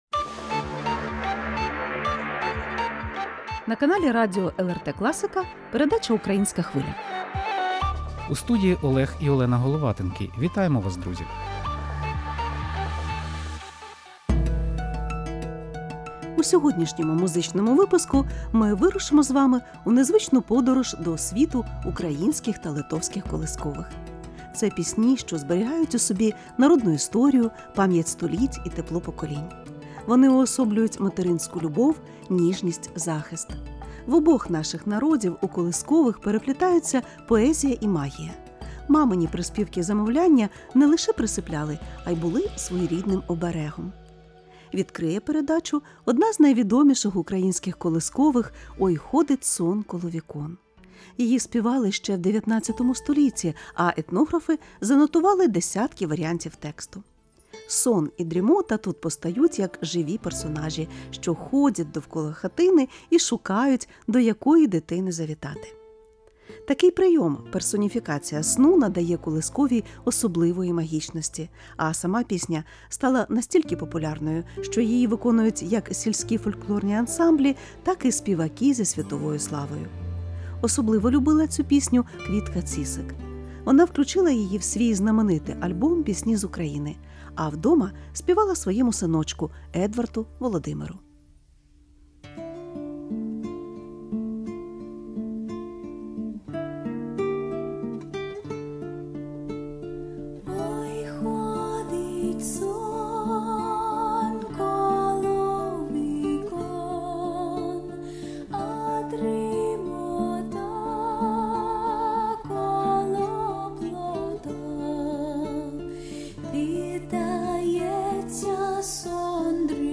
У передачі звучать також сучасні інтерпретації давніх народних колиханок, виконані як на канклесі, так і в поєднанні з джазом і електронікою.